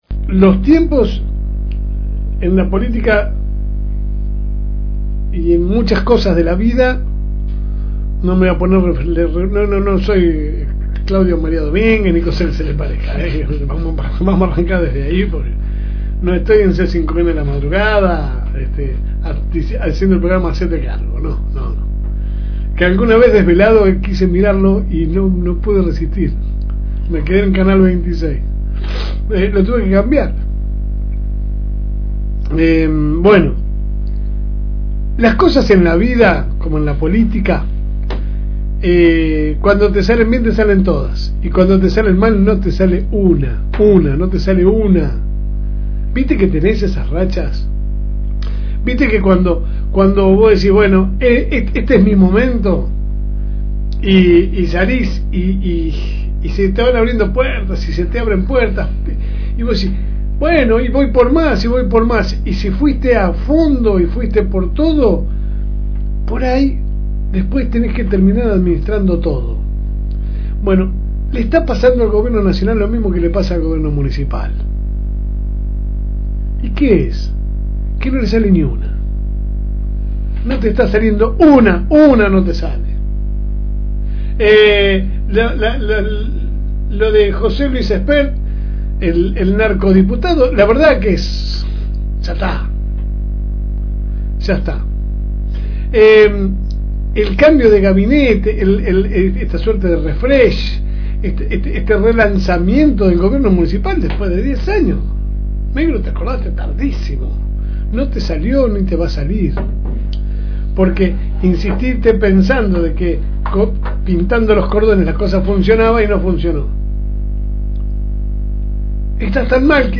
AUDIO – Pequeña reflexión de viernes – FM Reencuentro